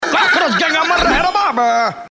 twitch-follower_v8bUgYm.mp3